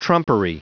Prononciation du mot trumpery en anglais (fichier audio)
Prononciation du mot : trumpery